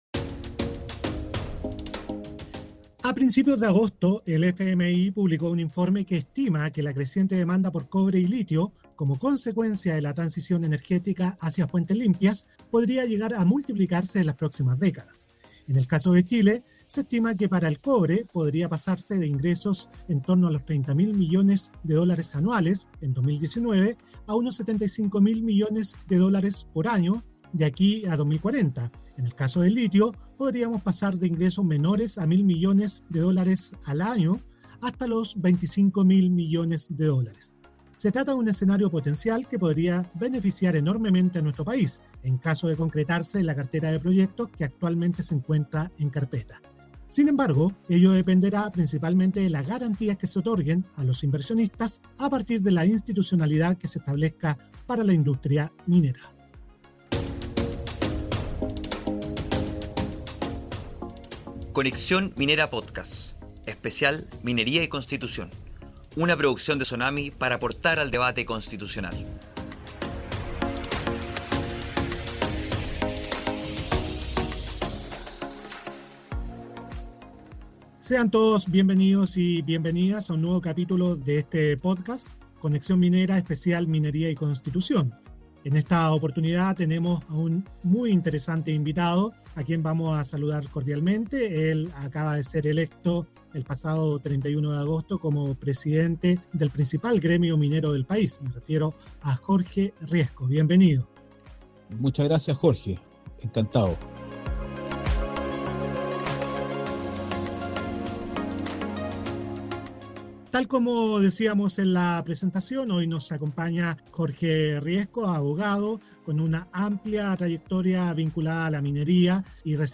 Como parte del debate constitucional, SONAMI ha comenzado una serie de podcasts en que se entrevistan a diversos personeros del ámbito minero y otros sectores para analizar el borrador y la futura nueva Constitución, que será sometida a plebiscito el 4 de septiembre.